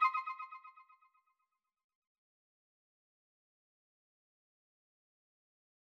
back_style_4_echo_003.wav